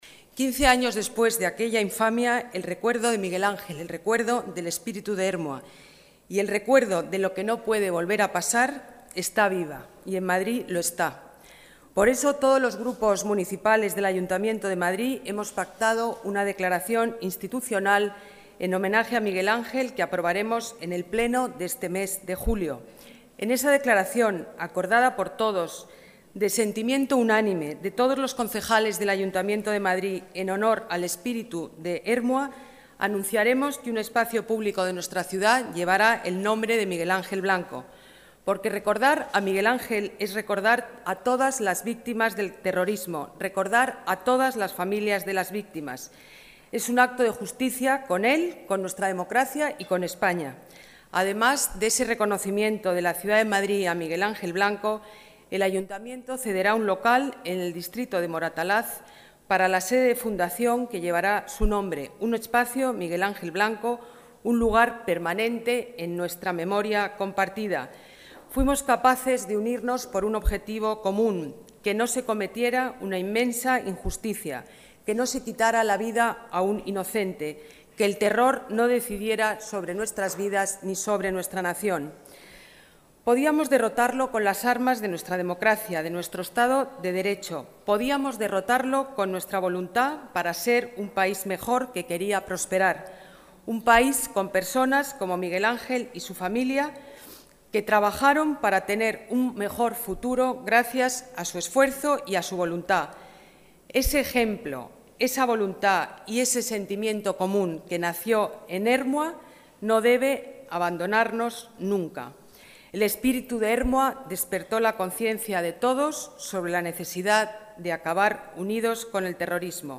Más archivos multimedia Ana Botella: El recuerdo de Miguel Ángel Blanco está vivo en Madrid Vídeo de Ana Botella en el acto institucional en homenaje a Miguel Ángel Blanco Más documentos Discurso de la alcaldesa Ana Botella en el homenaje a Miguel Ángel Blanco